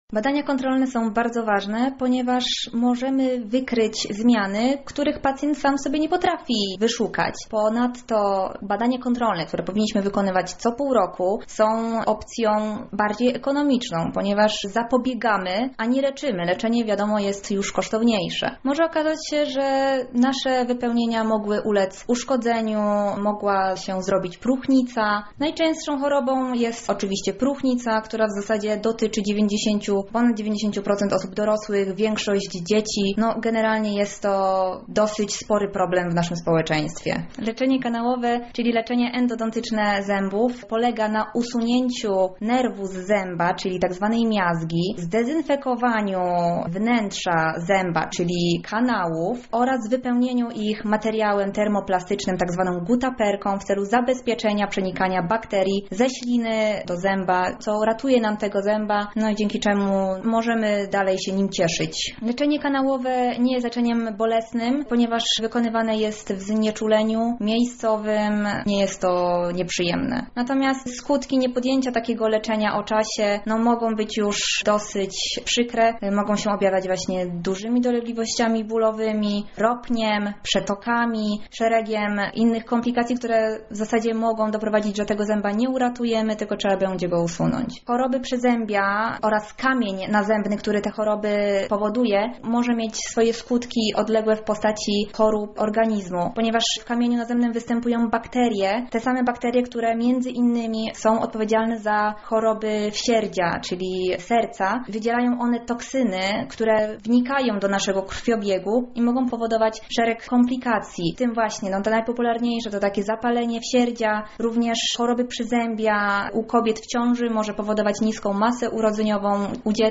dentysta lublin